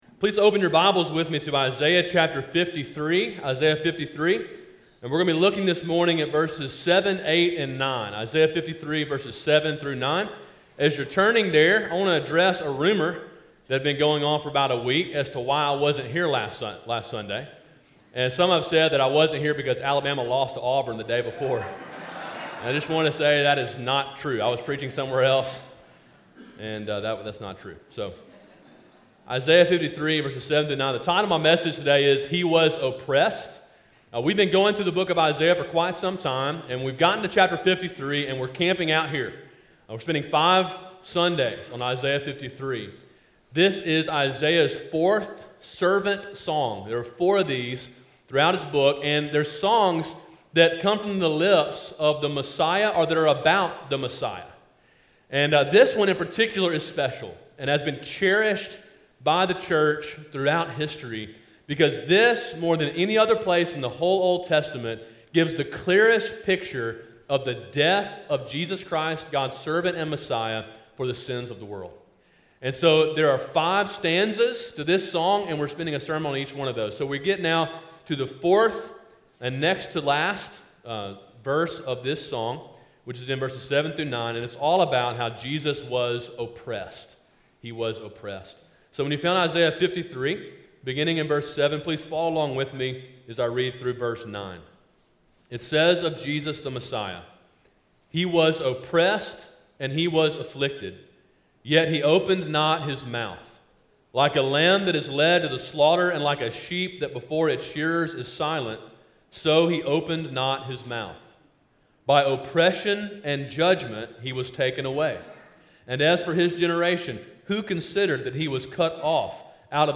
Sermon: “He Was Oppressed” (Isaiah 53:7-9) – Calvary Baptist Church